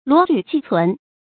罗缕纪存 luó lǚ jì cún
罗缕纪存发音
成语注音 ㄌㄨㄛˊ ㄌㄩˇ ㄐㄧˋ ㄘㄨㄣˊ